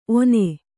♪ one